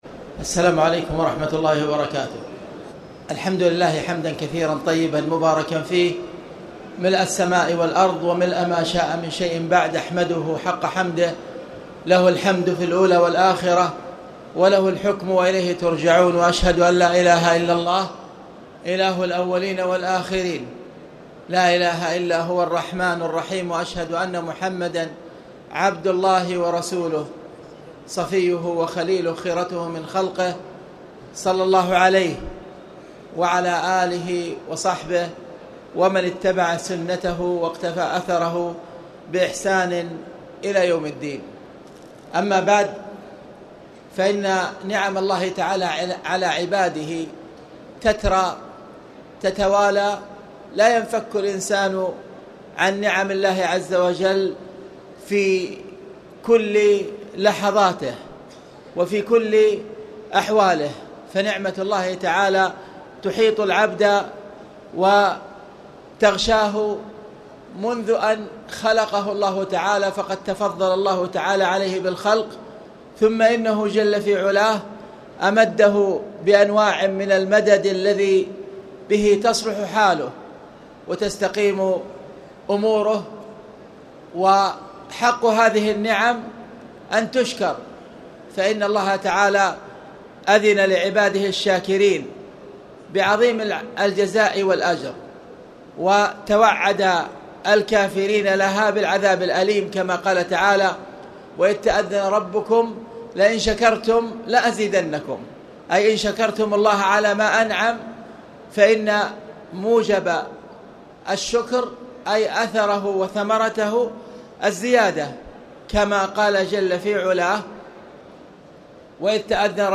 تاريخ النشر ١٣ رمضان ١٤٣٨ هـ المكان: المسجد الحرام الشيخ